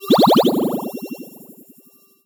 potion_bubble_effect_brew_02.wav